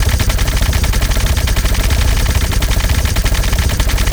Added more sound effects.
LASRGun_Plasma Rifle Fire Loop_02_SFRMS_SCIWPNS.wav